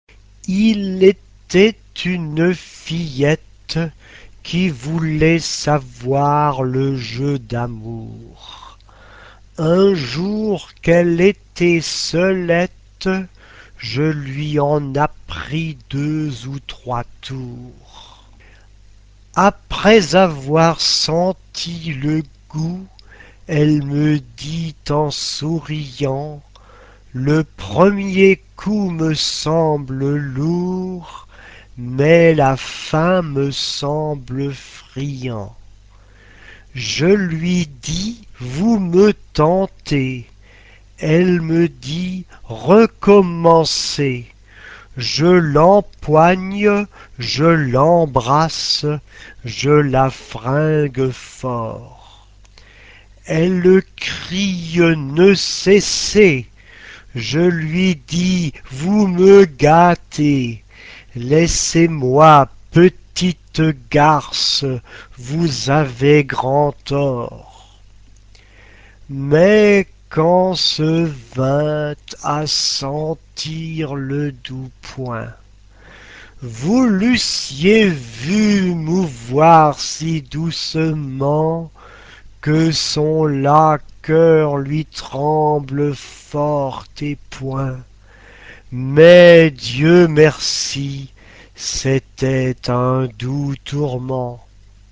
SATB (4 voces Coro mixto) ; Partitura general.
Canción. Renacimiento. Profano.
Carácter de la pieza : rápido ; pillo ; ligero
Tonalidad : si bemol mayor ; sol menor